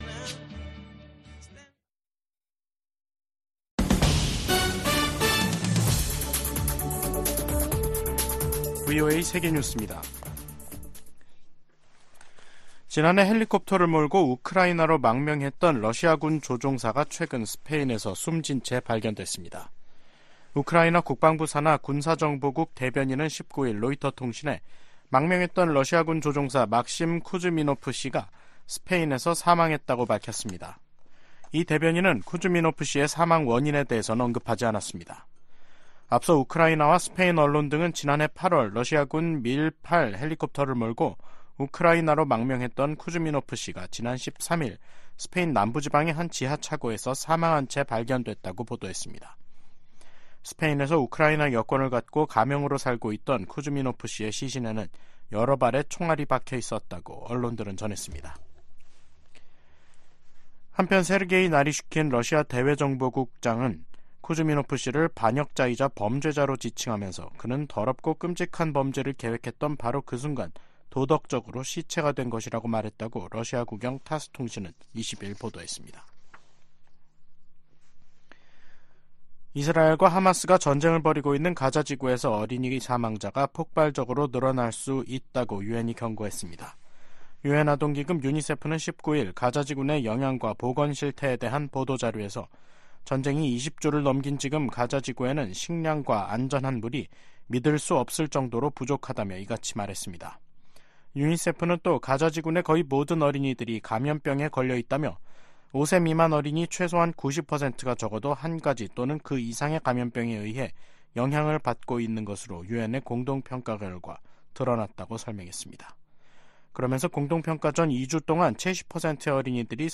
VOA 한국어 간판 뉴스 프로그램 '뉴스 투데이', 2024년 2월 20일 3부 방송입니다. 블라디미르 푸틴 러시아 대통령이 김정은 북한 국무위원장에게 러시아산 승용차를 선물했습니다. 미 국무부가 역내 긴장 고조는 미국 탓이라는 북한의 주장을 일축하고, 미국과 동맹의 연합훈련은 합법적이이라고 강조했습니다. 미 국무부는 유엔 북한인권조사위원회(COI) 최종 보고서 발표 10주년을 맞아 북한 정권에 인권 문제 해결을 촉구했습니다.